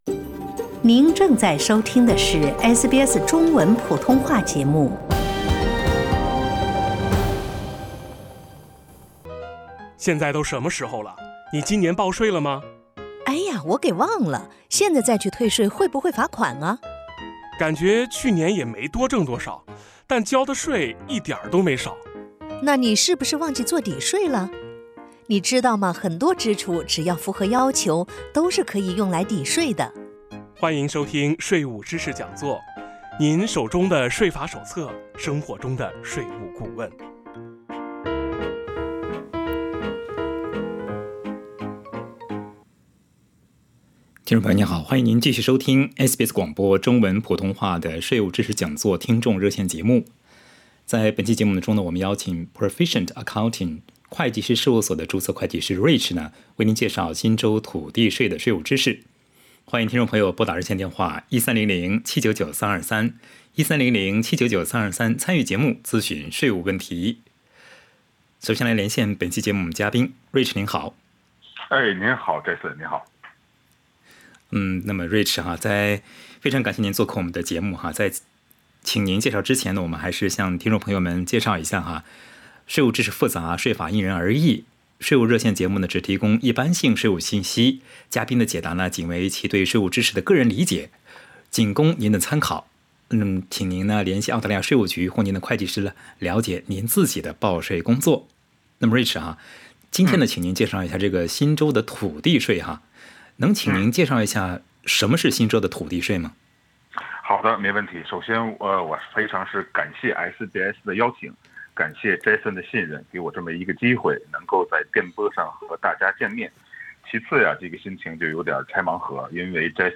在本期《税务知识讲座》听众热线节目中